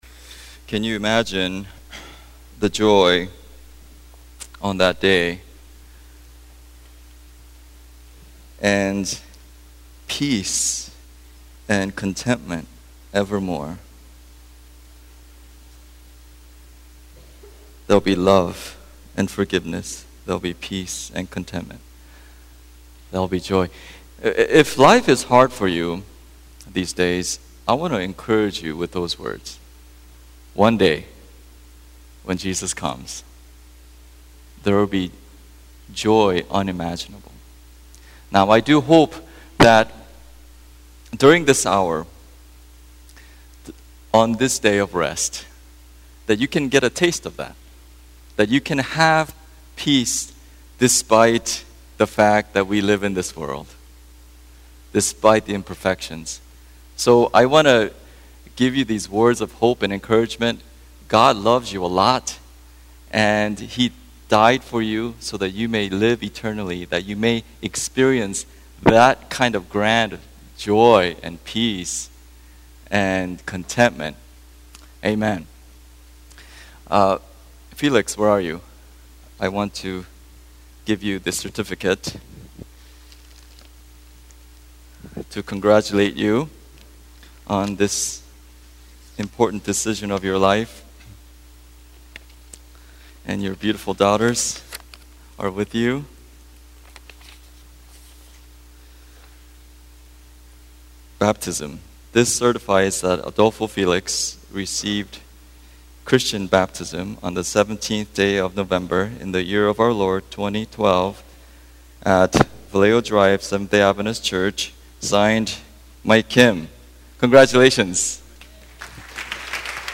Sermons 2012